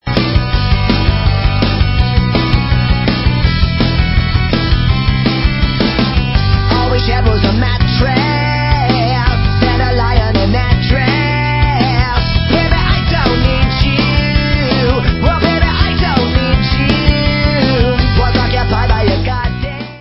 Rock/Punk